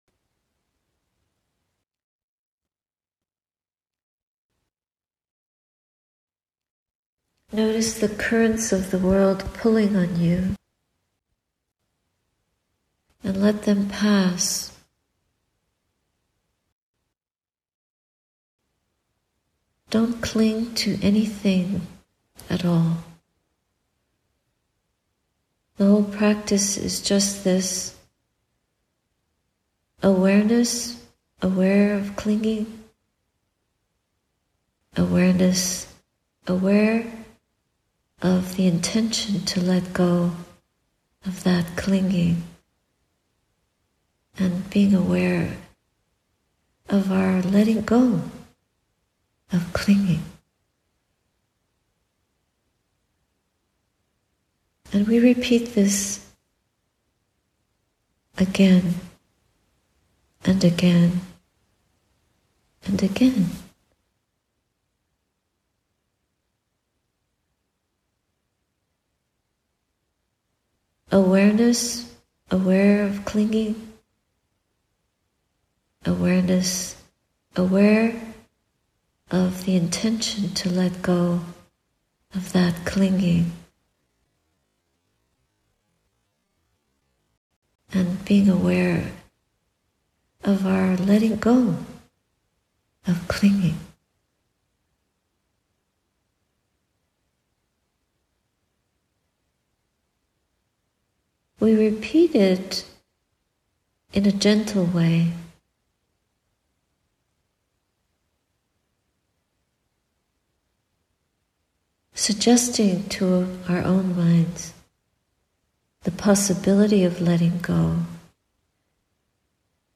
Living Peace Guided Meditation